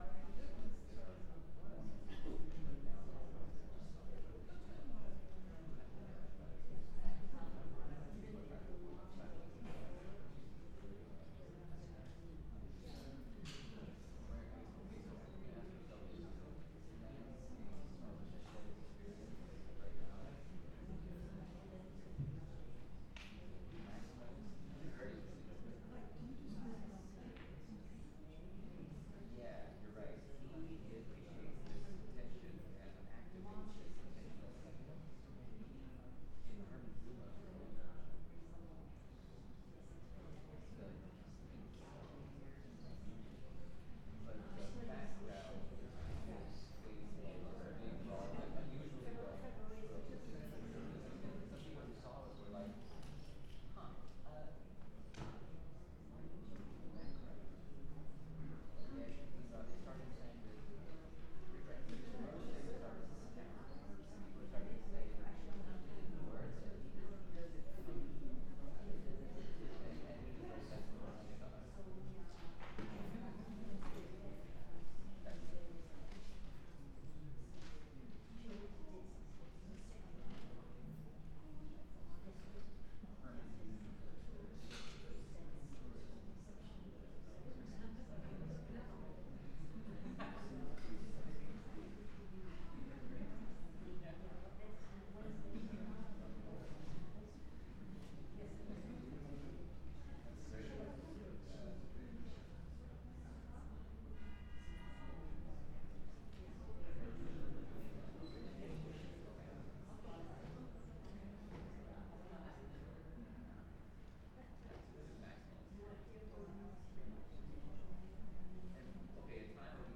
cello, voice